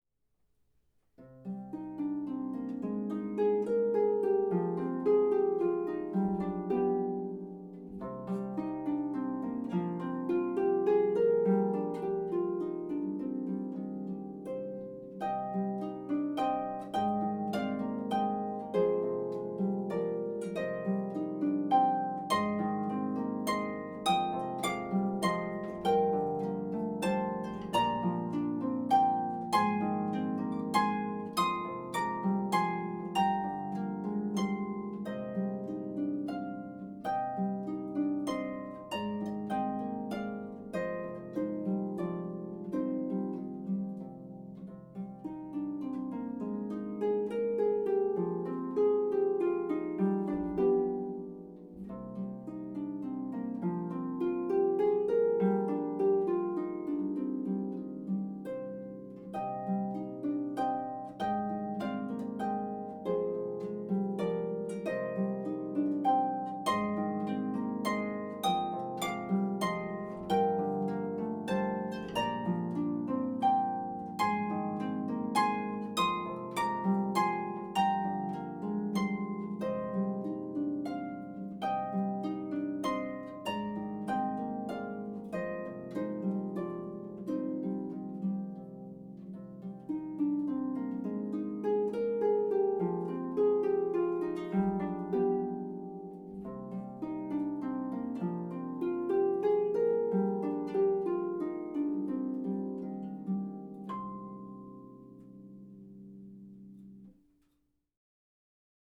traditional folk song
for solo pedal harp